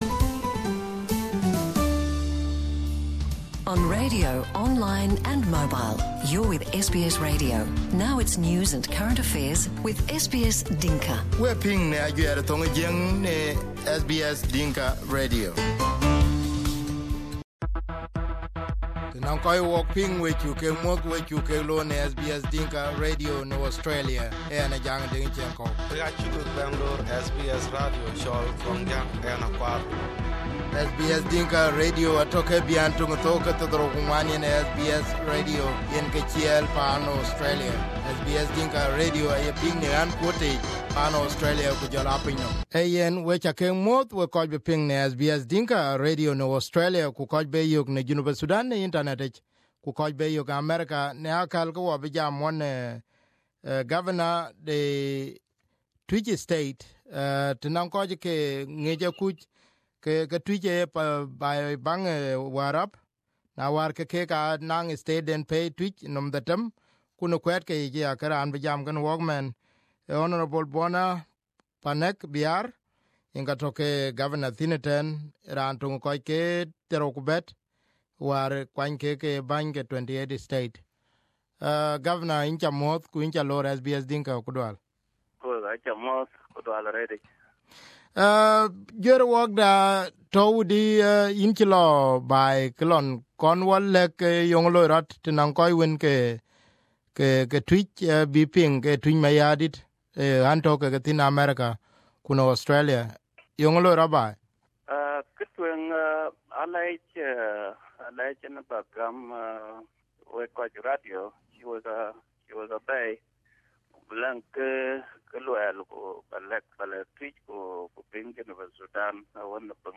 Interview with Twic State Governor Bona Panek
The shocking news to many of the Twic residents was the relocation of the capital city of the county of the State from Turalei to Mayen Abun. This relocation created a debate which Governor Panek says can only be resolved by the legislative assembly. Here is his first interview on SBS Dinka